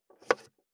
566まな板の上,包丁,ナイフ,調理音,料理,
効果音